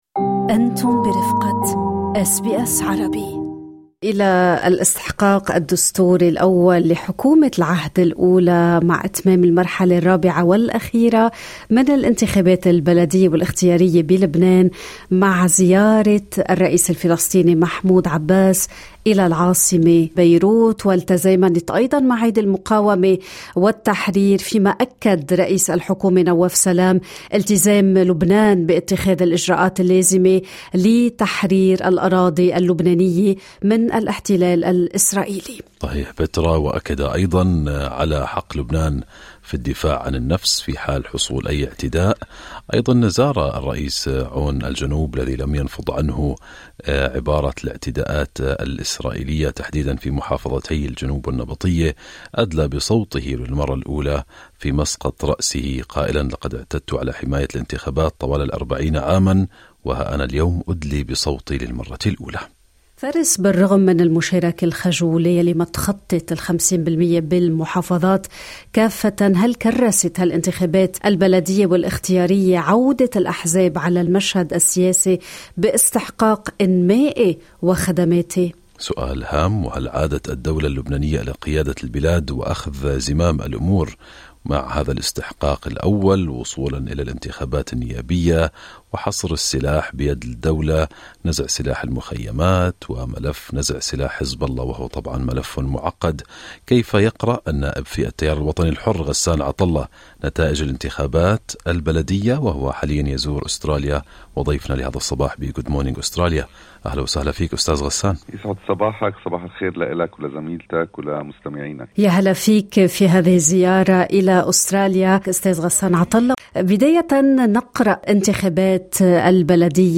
في لقاء